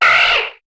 Cri de Zigzaton dans Pokémon HOME.